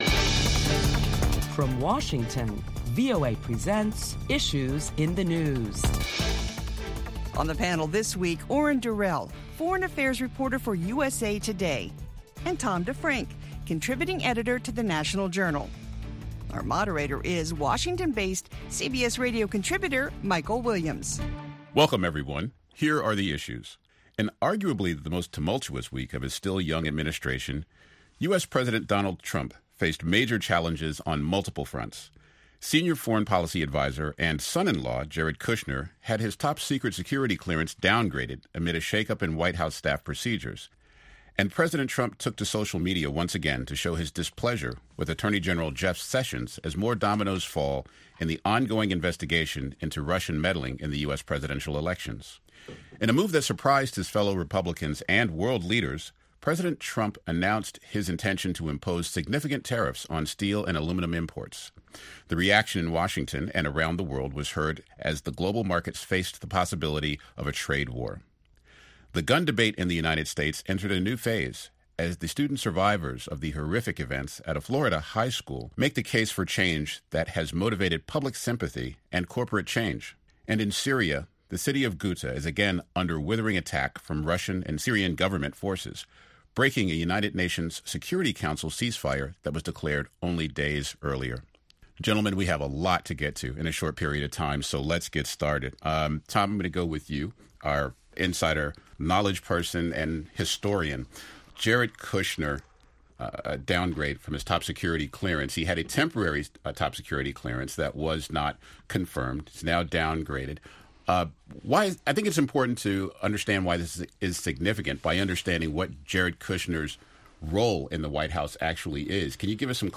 This week on Issues in the News, top Washington journalists talk about the week's headlines including the latest on the Russia probe and the possibility of a trade war with new tariffs announced on steel.